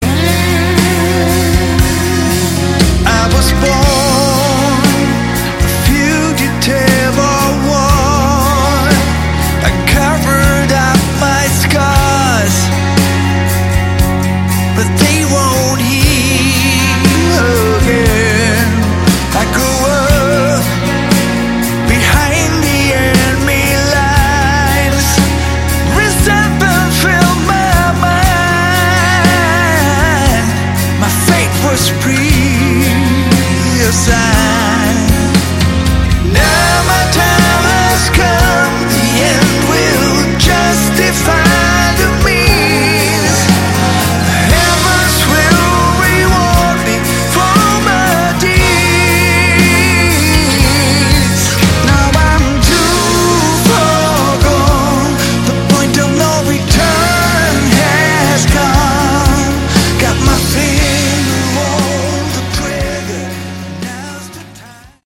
Category: Hard Rock
guitar, vocals, keyboards
bass
drums